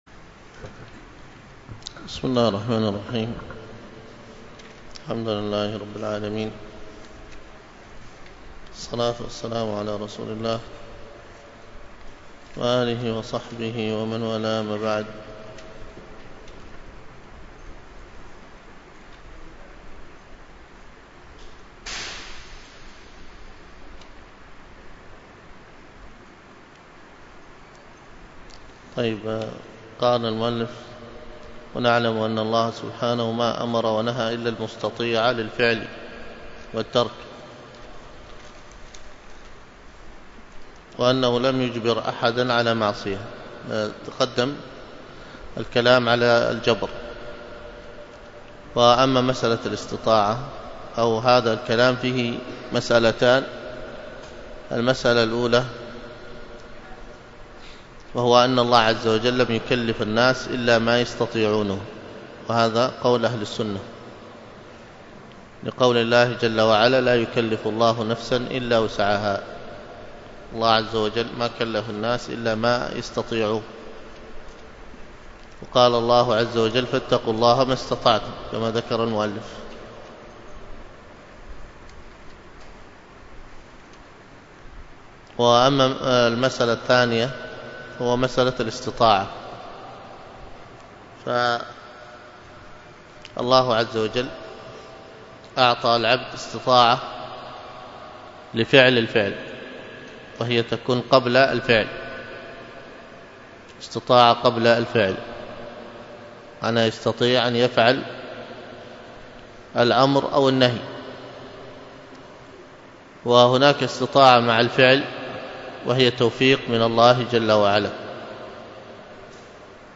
الدروس العقيدة ومباحثها